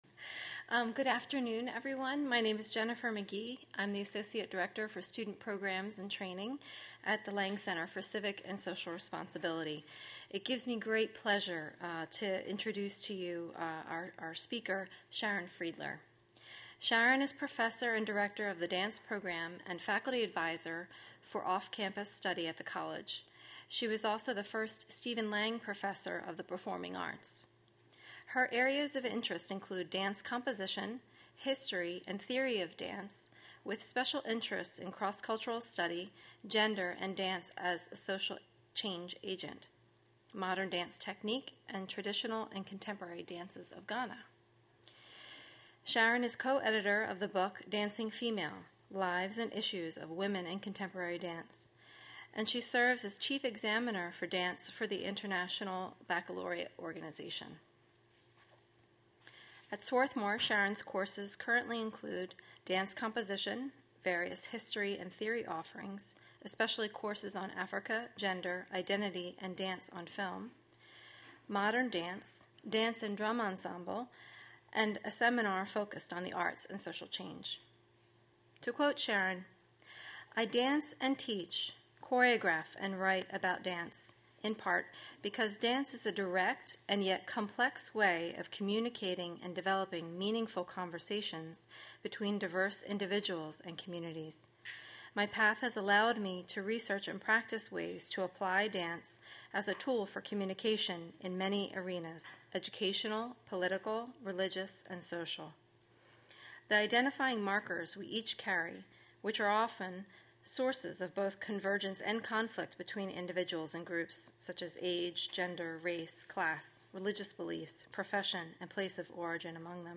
Several hundred people attended the College's fourth annual Garnet Homecoming and Family Weekend.